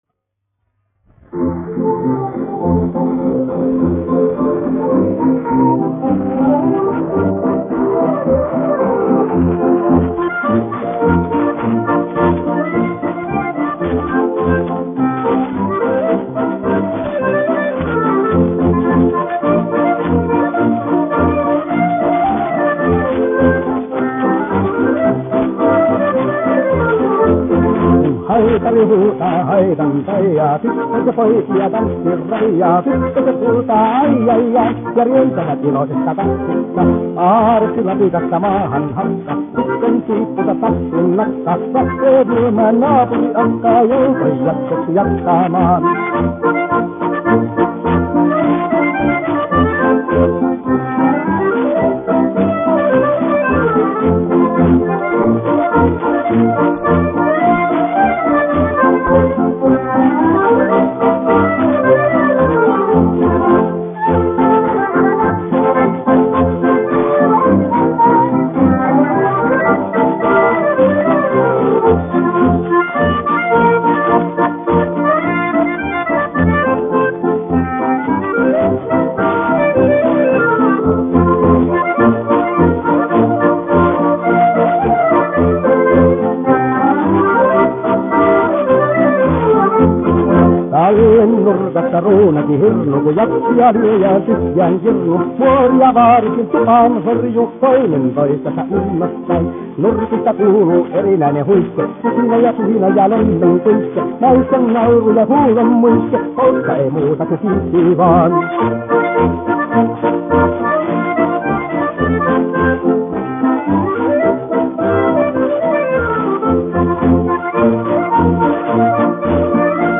1 skpl. : analogs, 78 apgr/min, mono ; 25 cm
Polkas
Populārā mūzika
Latvijas vēsturiskie šellaka skaņuplašu ieraksti (Kolekcija)